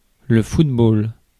ÄäntäminenFrance (Paris):
• IPA: [lə fut.bol]